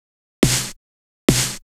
Techno / Drum / SNARE032_TEKNO_140_X_SC2.wav